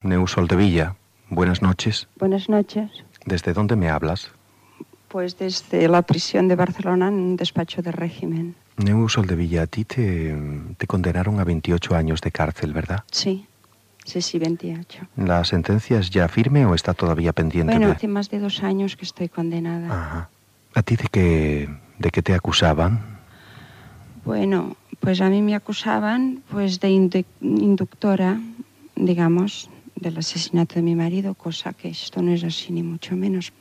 Fragment de l'entrevista